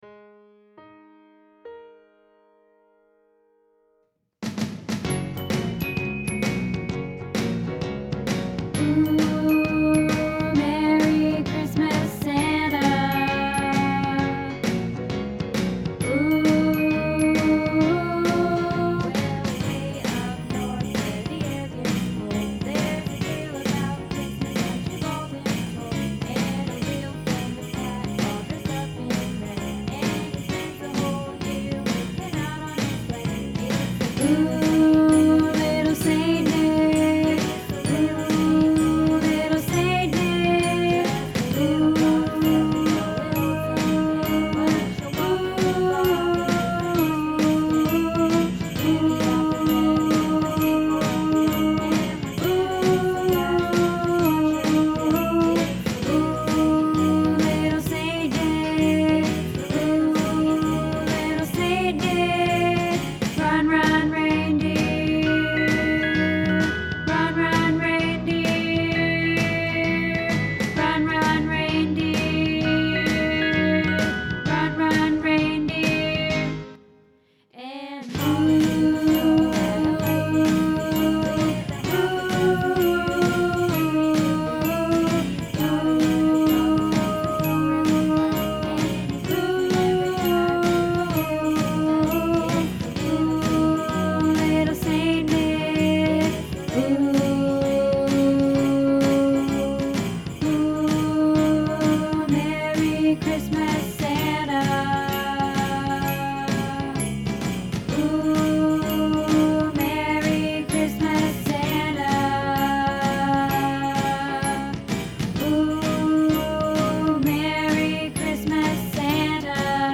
Little Saint Nick – Tenor | Happy Harmony Choir